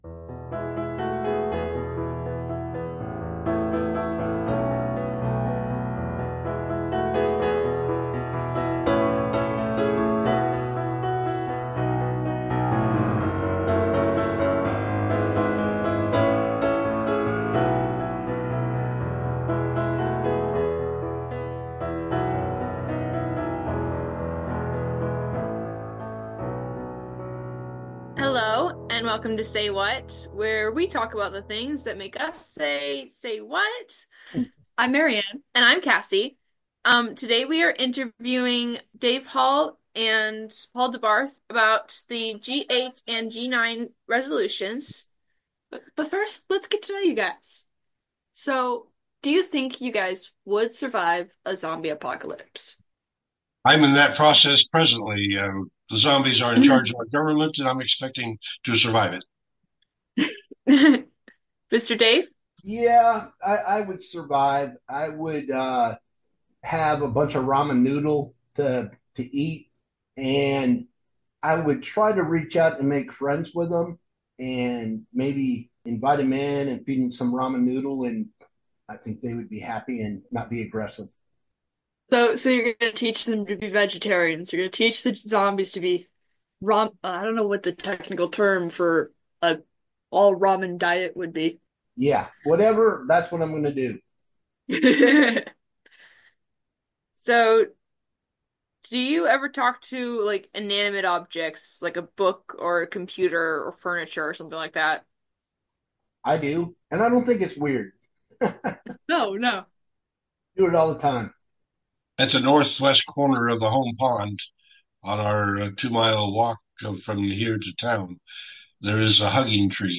871 | Grounds for Peace | 2025 World Conference Reflections